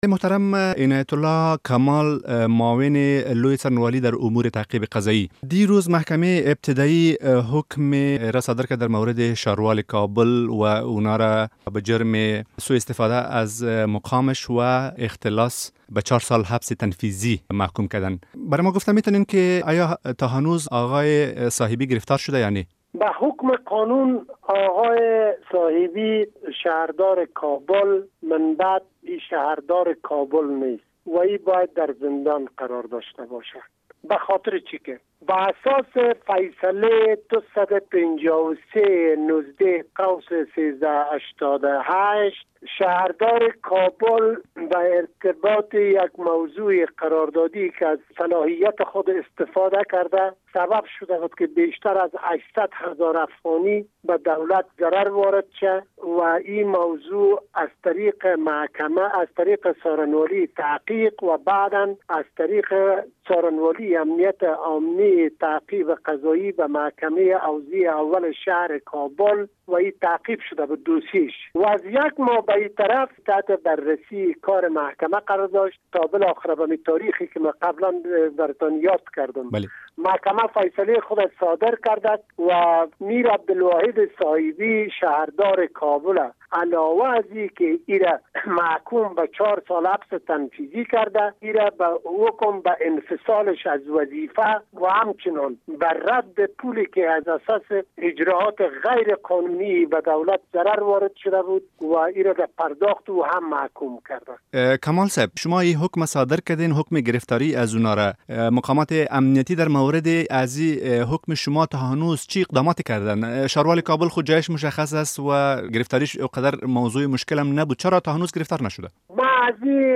مصاحبه با عنایت الله کمال معاون لوی څارنوالی افغانستان